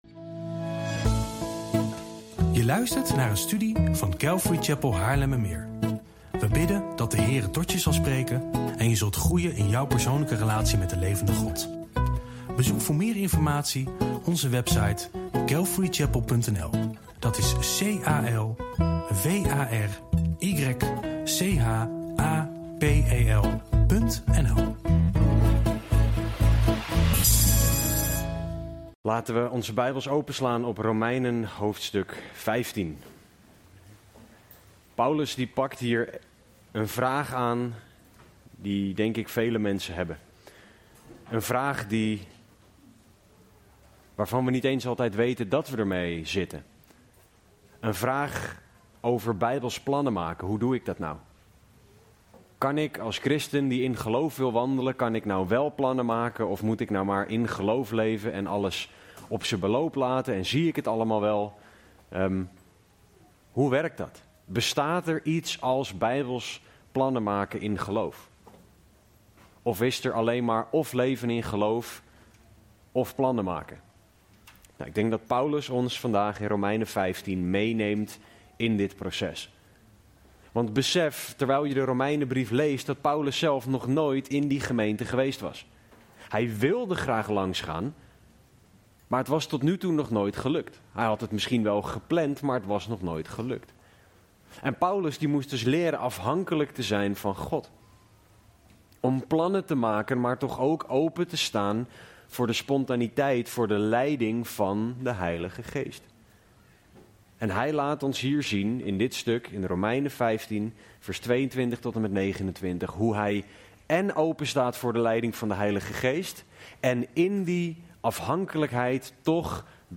Bijbelstudie en uitleg -